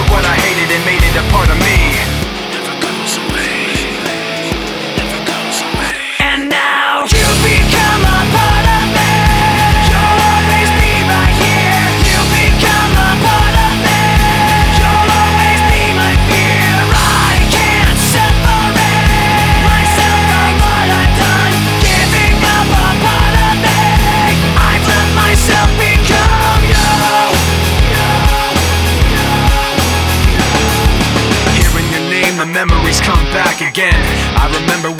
Hard Rock Rock Alternative Metal
Жанр: Рок / Альтернатива / Метал